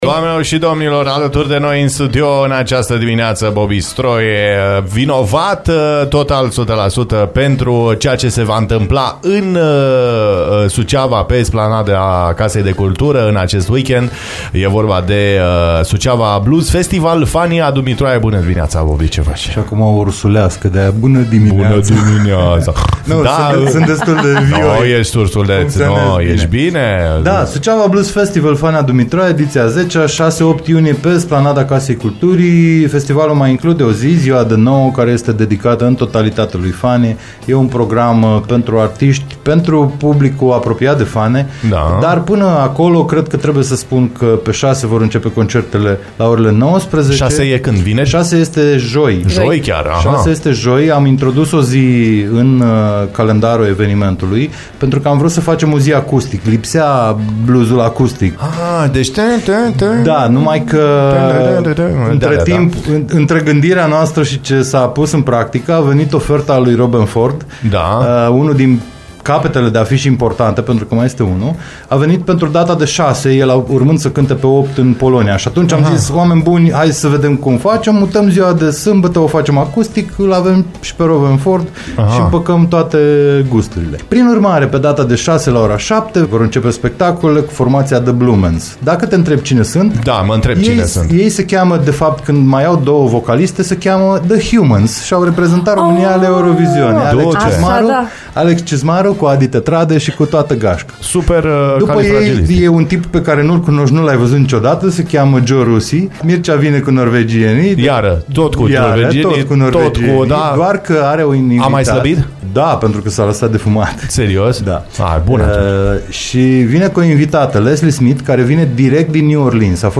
Evenimentul, care va avea loc între 6 și 8 iunie, a ajuns la ediția a 10 -a și a suscitat discuții interesante în studio.